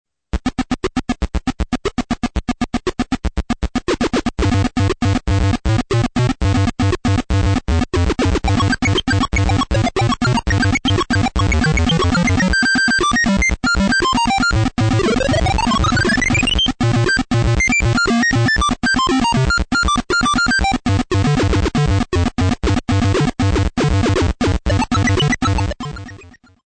Sonido digital en el PC-Speaker